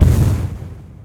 strider_step1.ogg